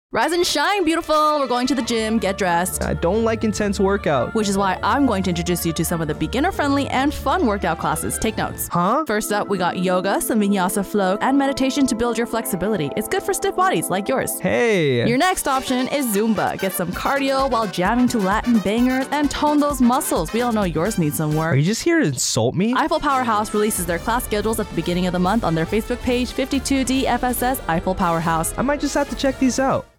30 second spot on classes offered at Eifel Powerhaus Gym on Spangdahlem Air Base.